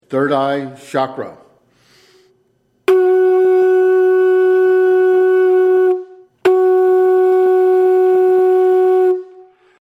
– All 23 conch shells without narration (Track 27)
Recorded at RadioStar Studios